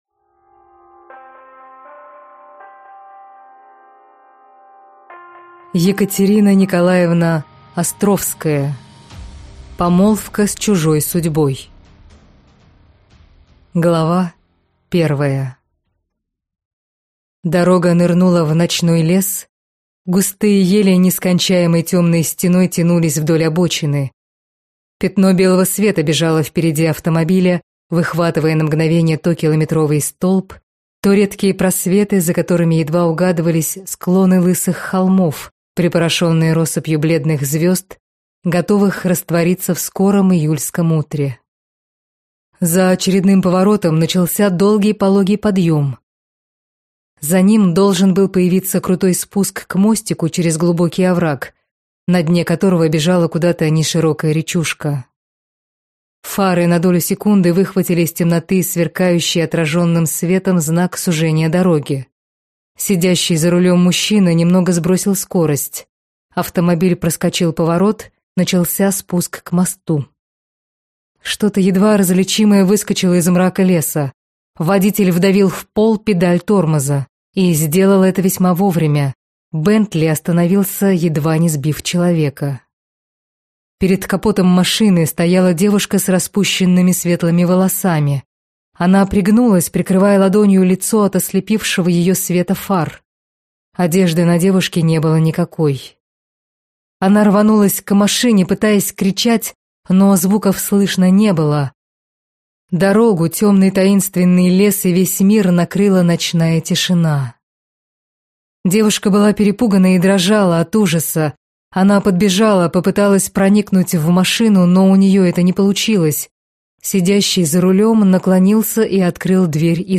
Аудиокнига Помолвка с чужой судьбой | Библиотека аудиокниг